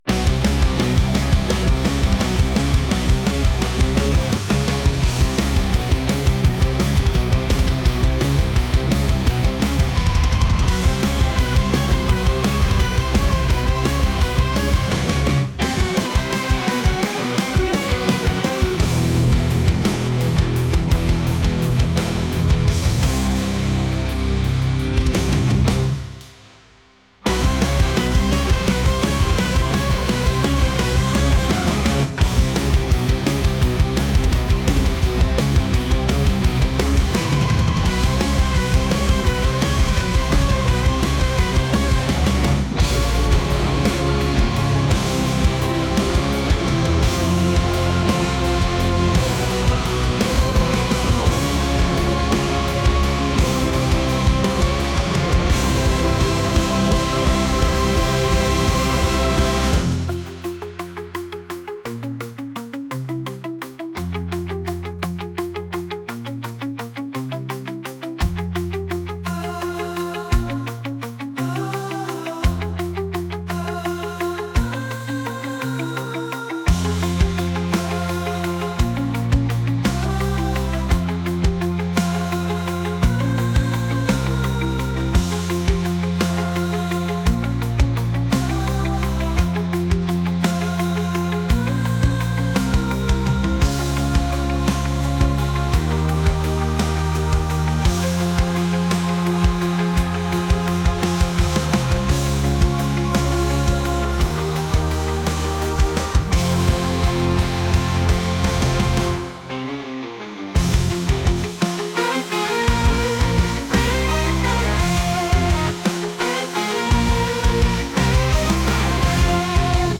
rock | alternative